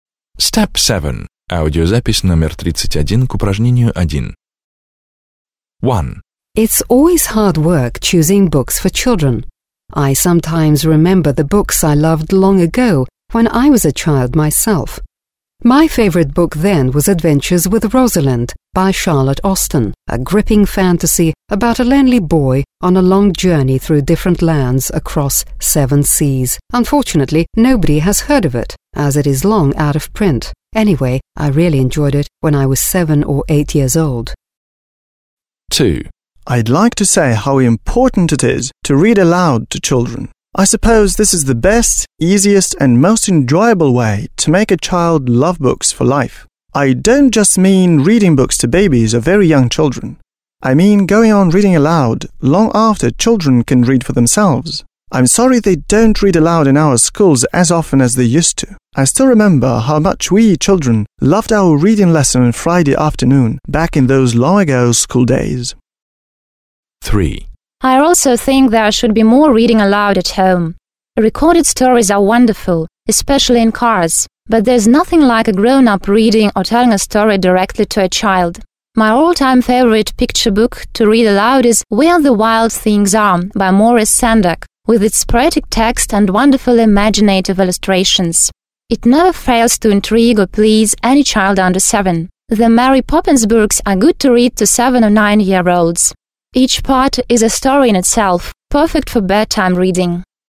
1. Listen to three people speaking about children’s books, (31), and match what they say with the statements below.